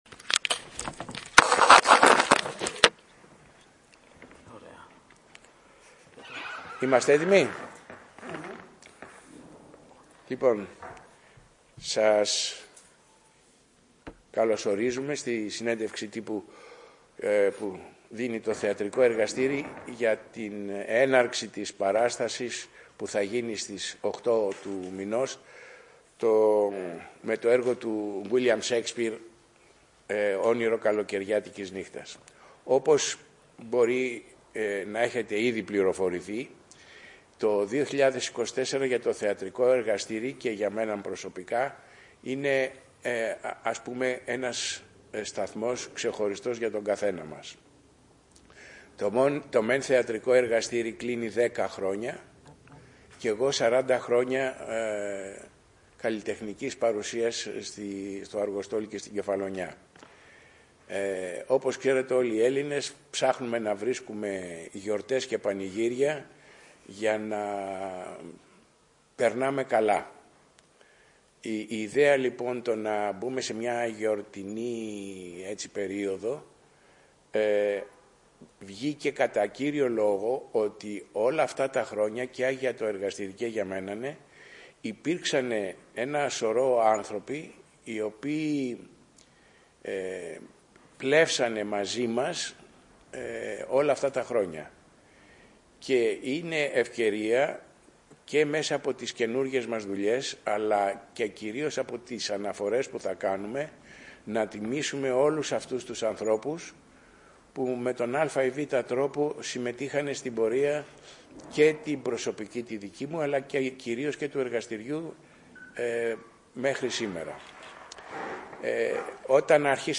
Μας συστήθηκαν οι συνεργάτες του που τον πλαισίωναν λέγοντας ο καθένας τον ρόλο του και πως αισθάνονται ποΤέχνηςυ επιτέλους θα παίξουν μπροστά στο κοινό Σαίξπηρ.